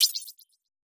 Bouncy Positve Game Item Pick Up.wav